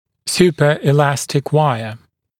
[ˌs(j)uːpəɪ’læstɪk ‘waɪə][ˌс(й)у:пэи’лэстик ‘уайэ]суперэластичная дуга